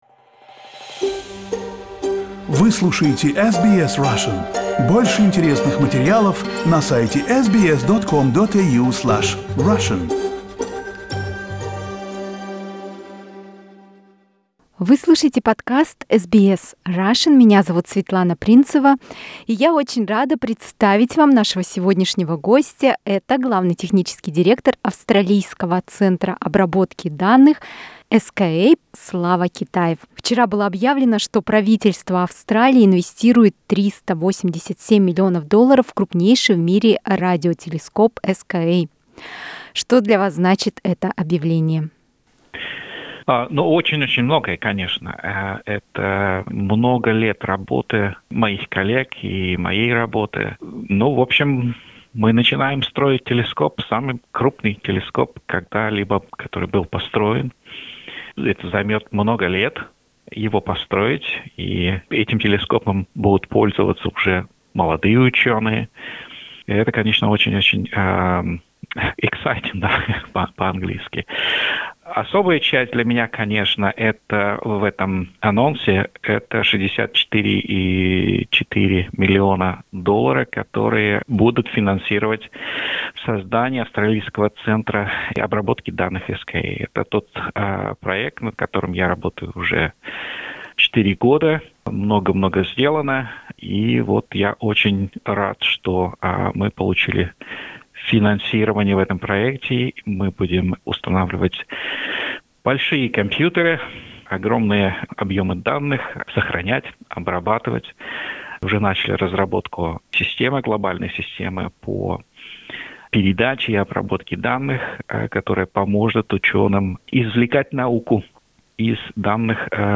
В интервью мы обсудили: